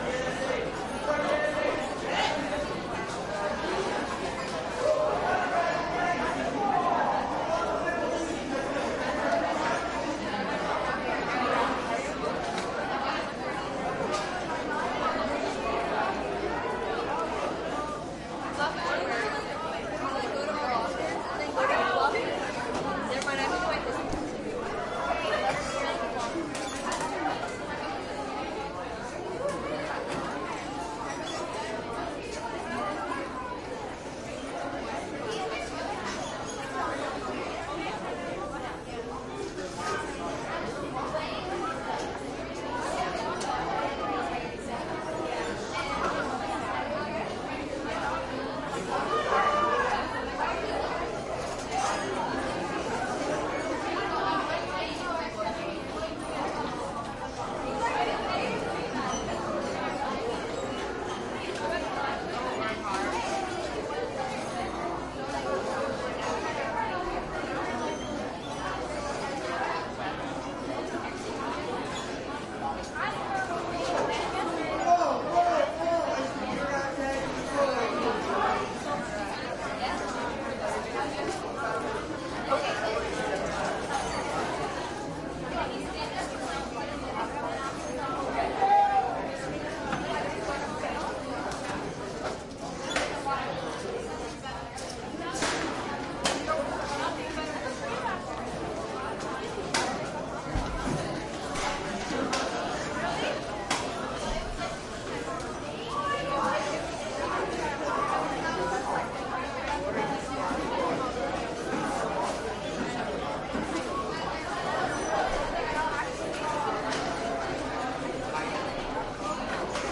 描述：这是为中国电影录制的单曲“walla” neumann U 87 一群配音艺术家...... 它的普通话 但有一个上海扭曲
Tag: 亲密耳语 中国 沃拉